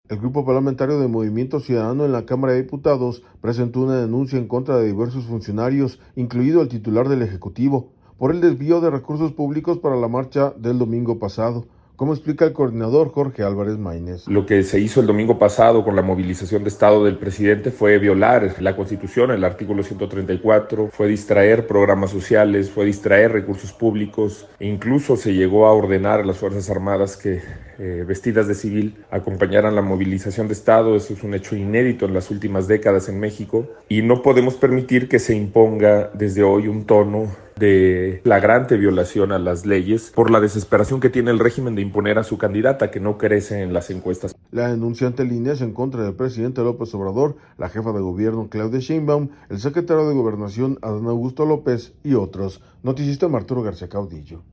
El Grupo Parlamentario de Movimiento Ciudadano en la Cámara de Diputados, presentó una denuncia en contra de diversos funcionarios, incluido el titular del Ejecutivo, por el desvío de recursos públicos para la marcha del domingo pasado, como explica el coordinador Jorge Álvarez Maynez.